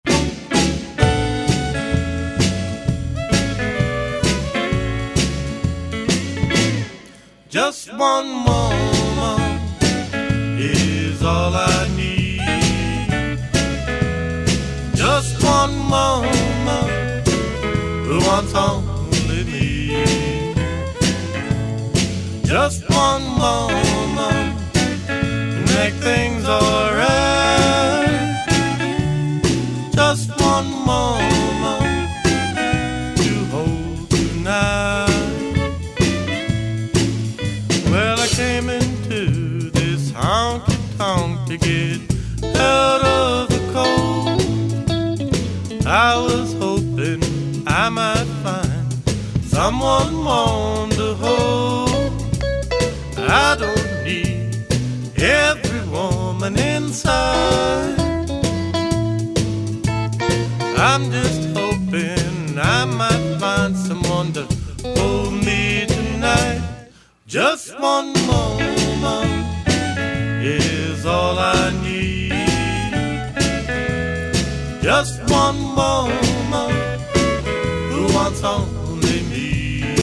mp3 / S / Alt Country